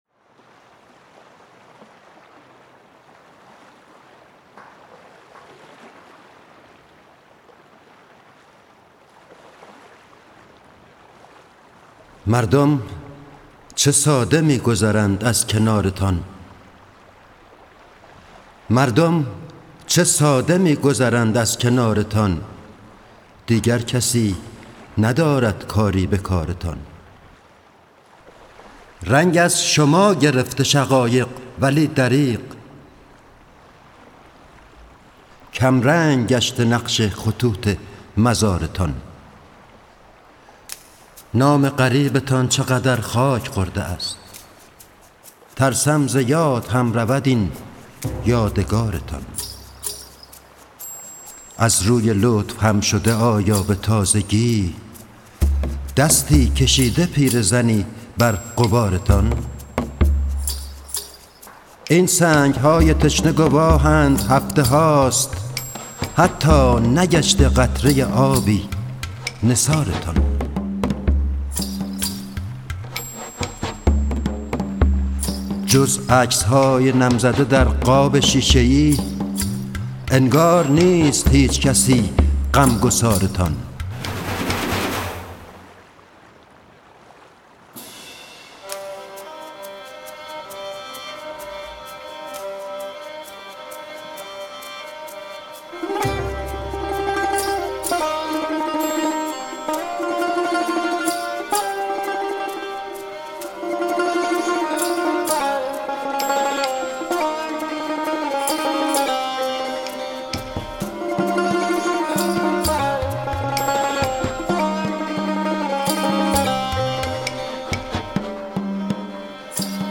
قطعه موسیقیایی
تنظیم برای ارکستر کوبه‌ای